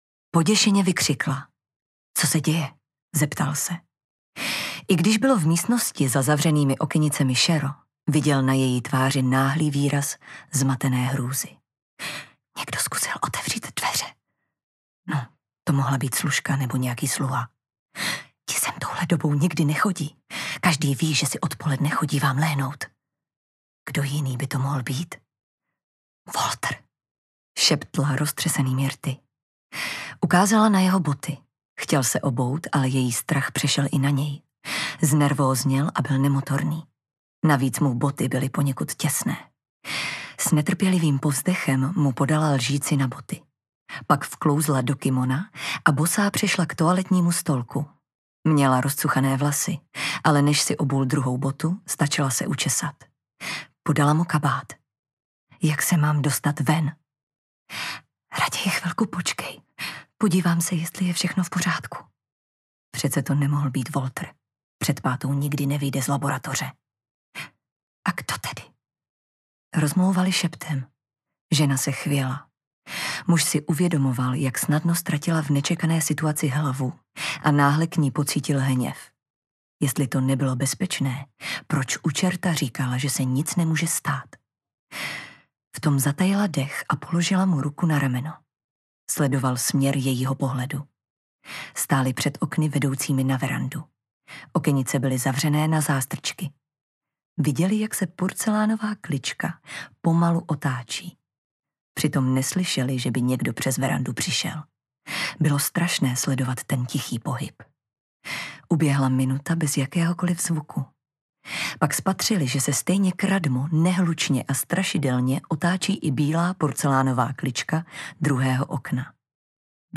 MP3 Audiobook